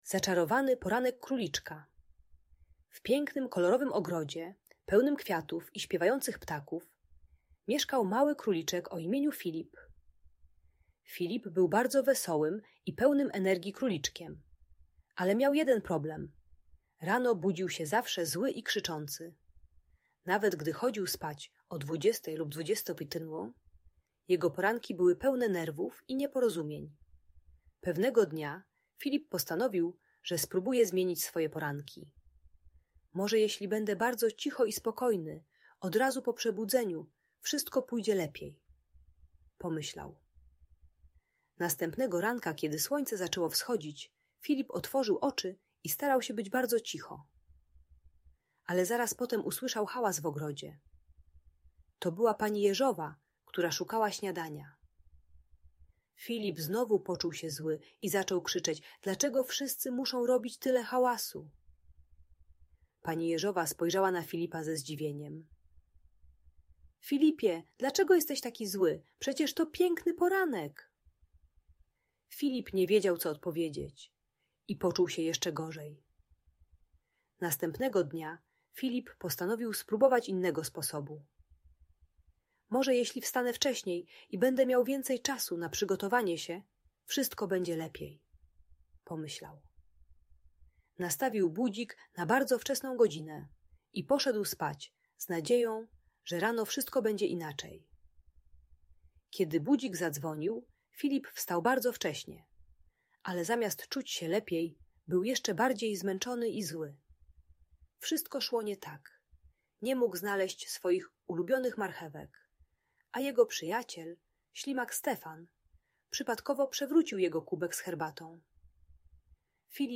Zaczarowany Poranek Króliczka - Audiobajka